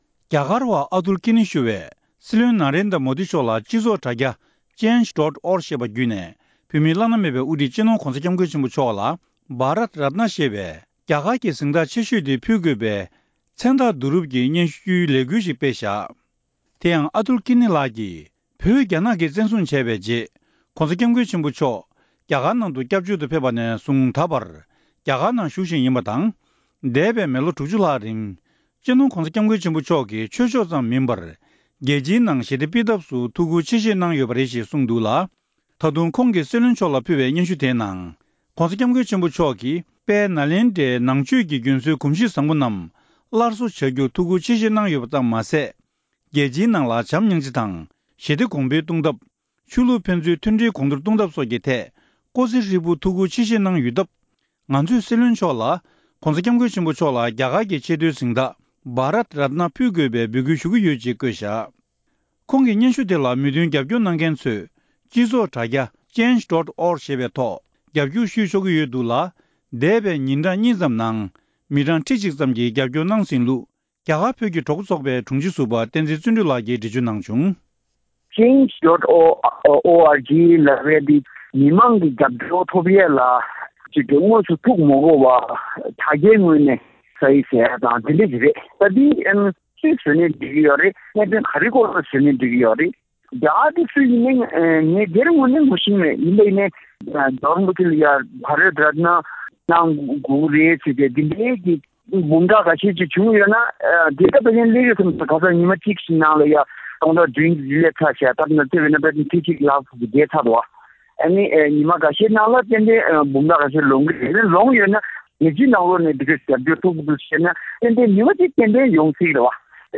བཀའ་འདྲི་ཞུས་ནས་ཕྱོགས་སྒྲིགས་ཞུས་པ་ཞིག་གསན་རོགས་གནང་།།